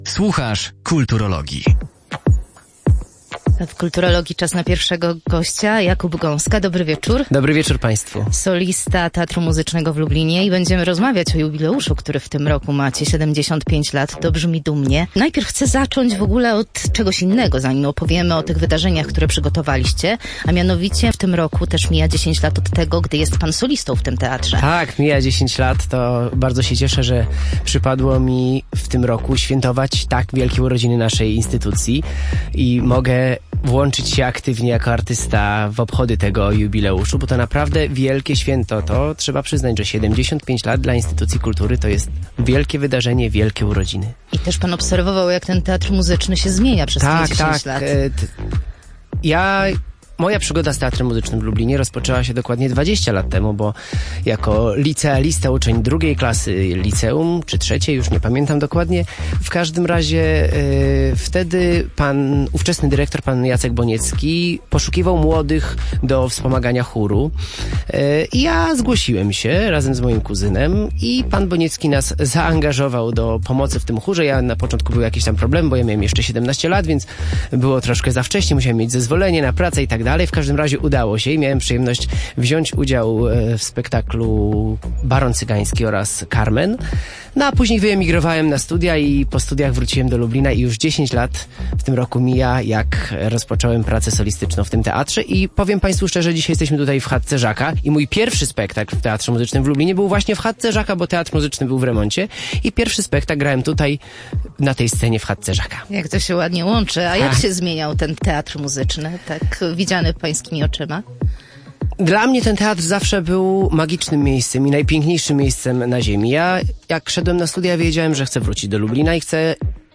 Fragment rozmowy poniżej: